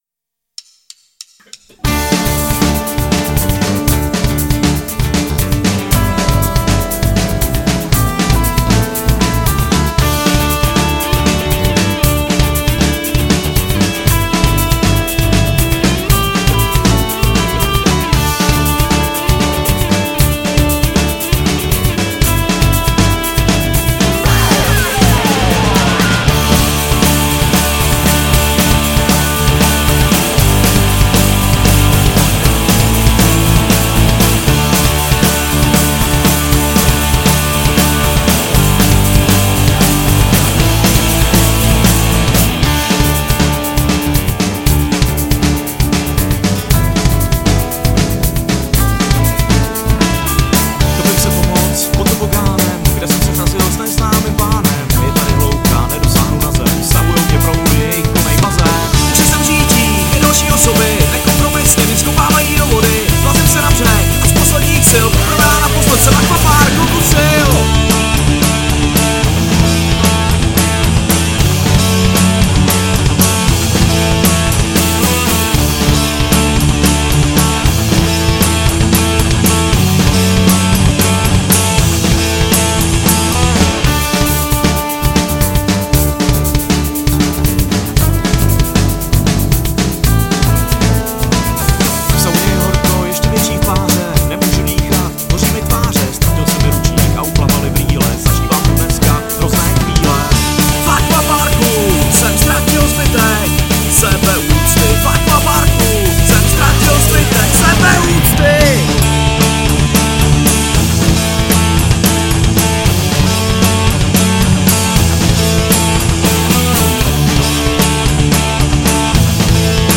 Žánr: Punk